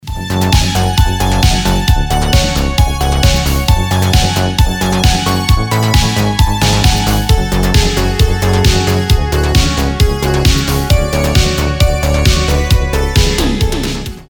танцевальные , без слов , synth pop , мелодичные
synthwave